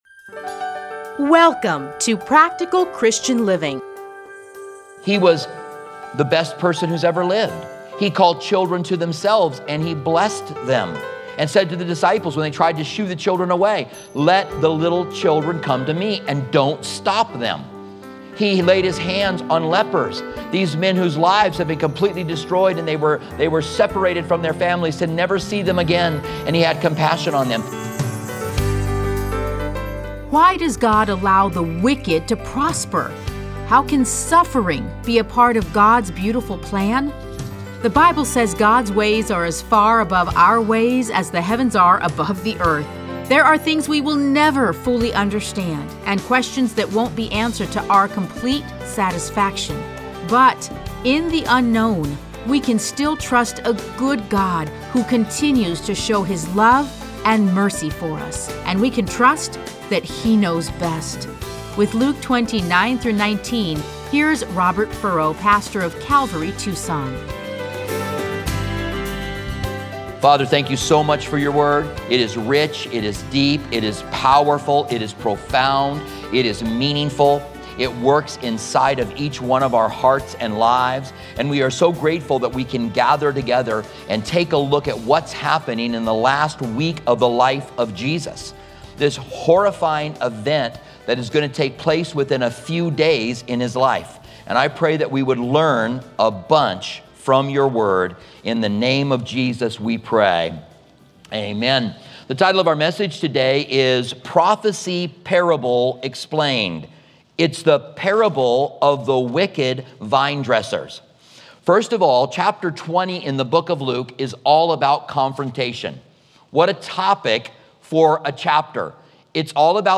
Listen to a teaching from Luke 20:9-19.